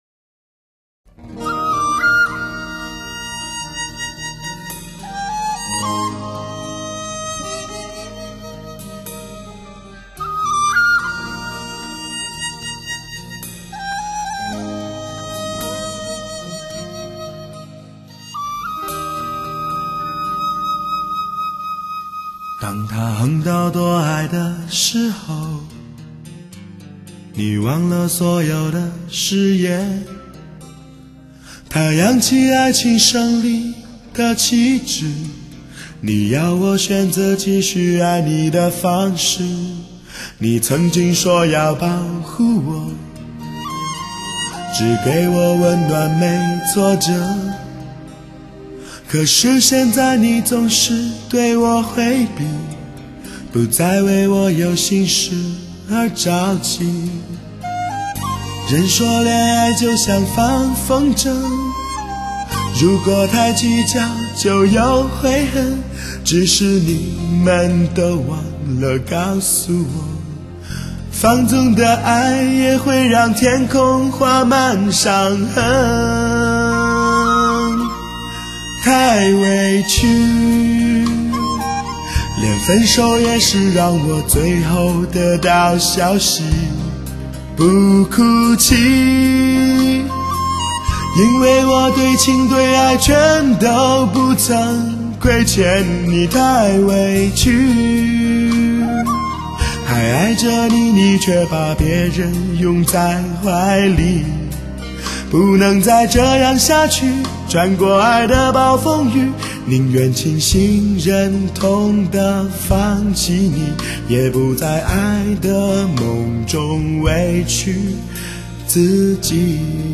声声激起男儿狂热 曲曲渗透铁汉柔情
（试听曲为低品质wma格式，下载为320k/mp3）